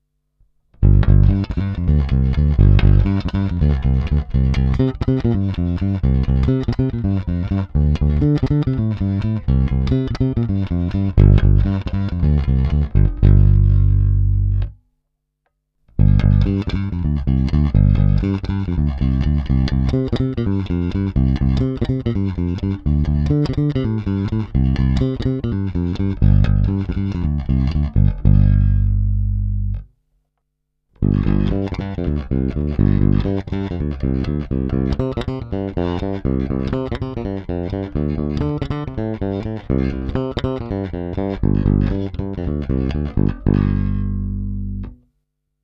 Není-li řečeno jinak, následující nahrávky jsou provedeny rovnou do zvukové karty a jen normalizovány, basy a výšky na nástroji nastavené skoro naplno.
Snímač u kobylky